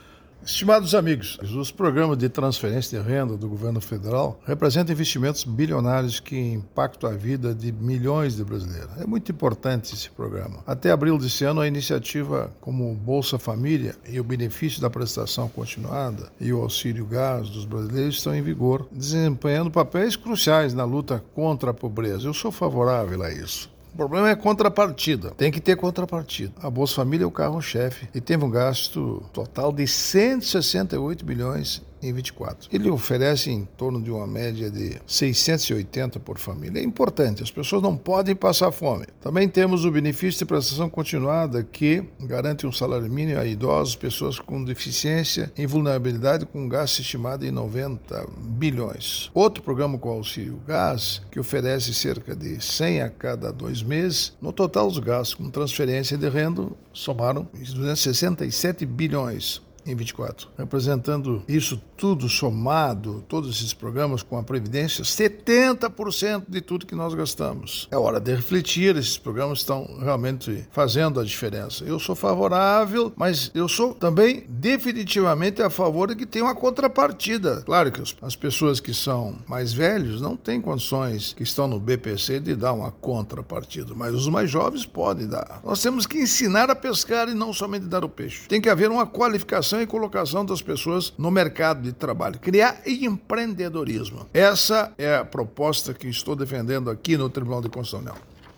Comentário do Ministro do TCU, Augusto Nardes.